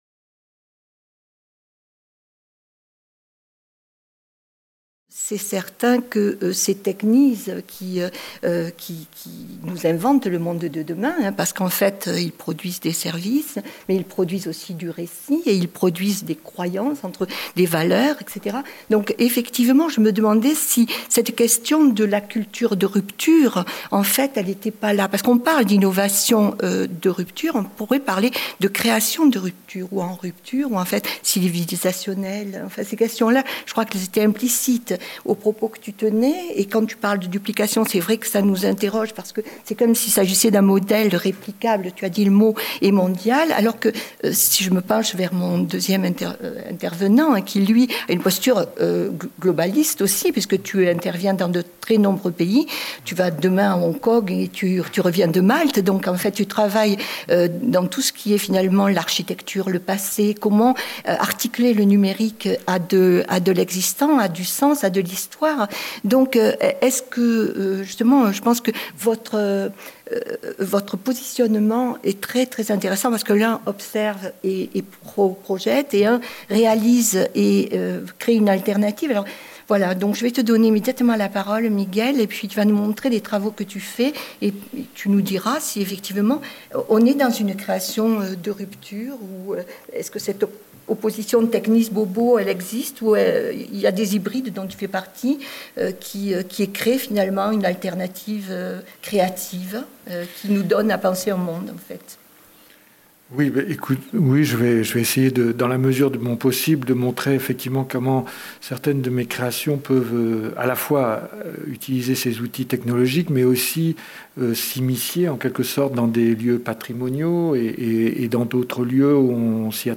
Vendredi 20 mai - Philharmonie, Salle de conférence 14H15 L’art et le numérique